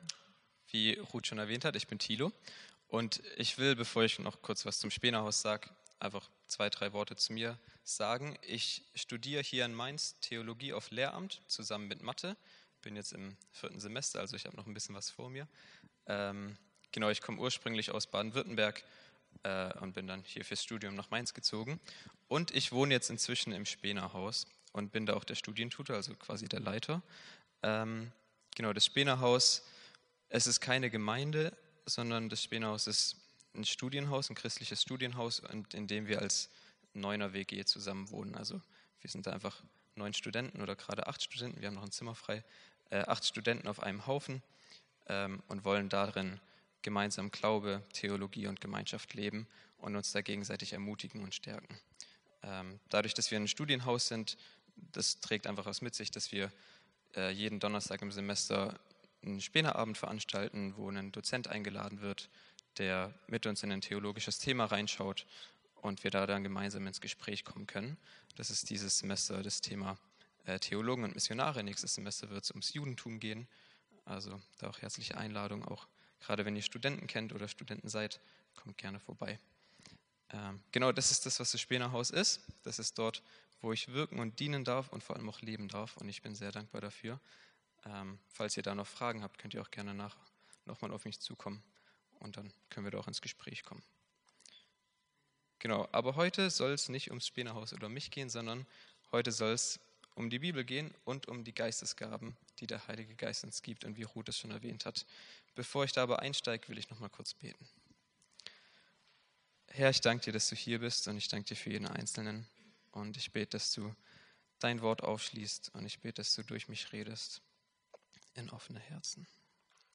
Predigt vom 26.01.2025